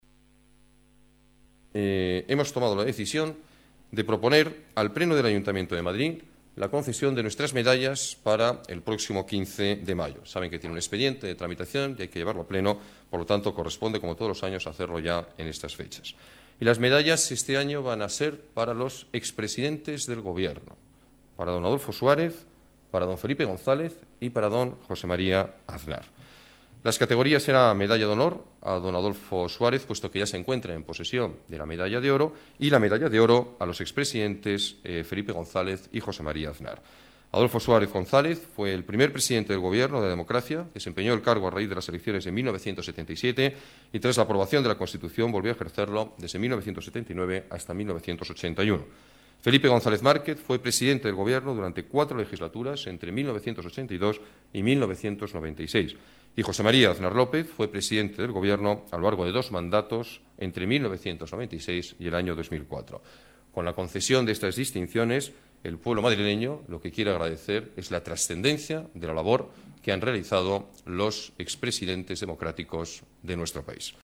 Nueva ventana:Declaraciones del alcalde, Alberto Ruiz-Gallardón: Medallas del Ayuntamiento ex presidentes